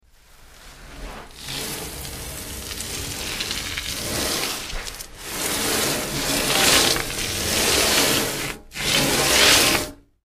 Sand Bag; Dragged, Scrape On Metal Deck.